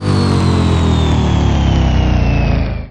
apowerdown.ogg